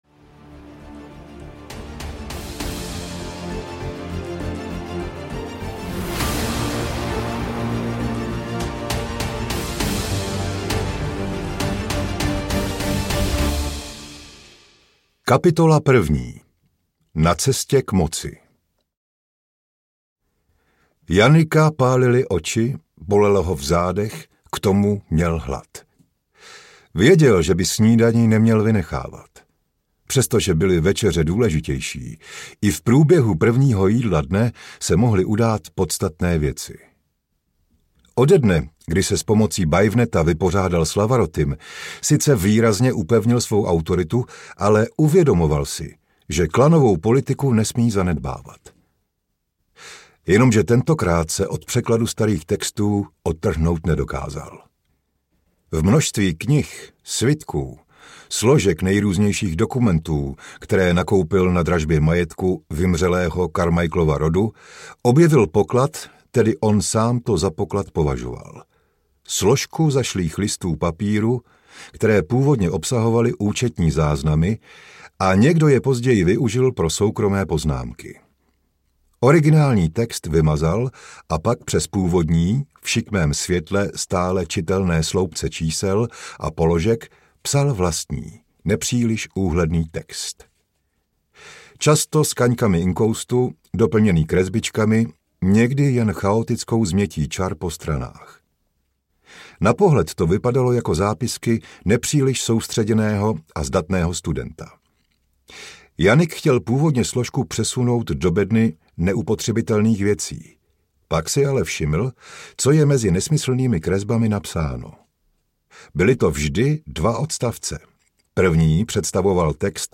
Bakly – V objetí smrti audiokniha
Ukázka z knihy